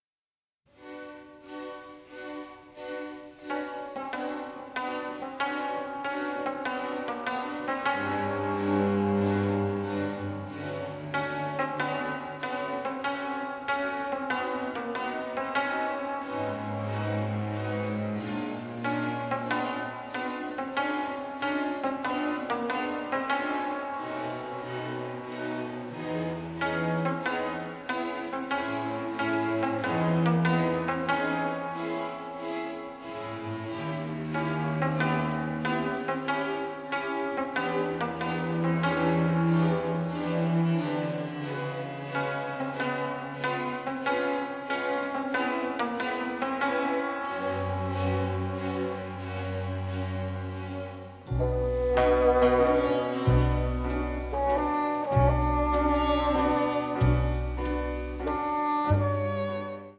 in full stereo for the first time.
the melancholic, the macabre and the parodic